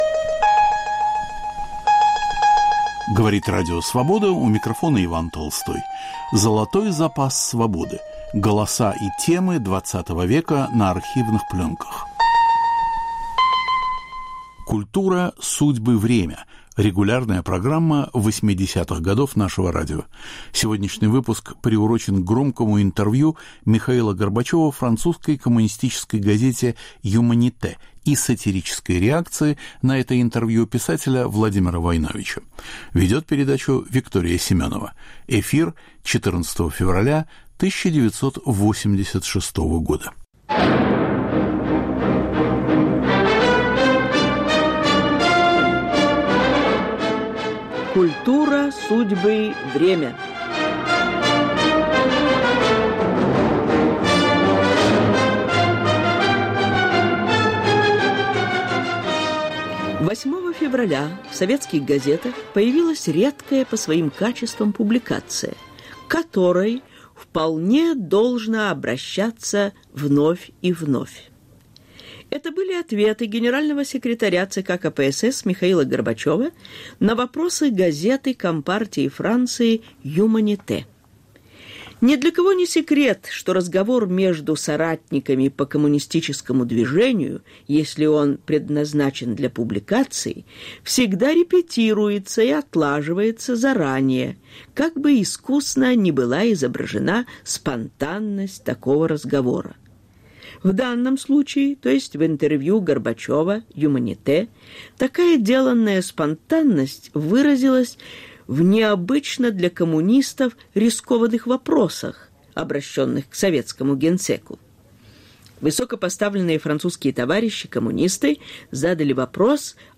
Интервью Михаила Горбачева "Юманите"
Французская коммунистическая газета задает неудобные вопросы советскому лидеру: Об Андрее Сахарове, политзаключенных, цензуре. Сатирический комментарий Владимира Войновича.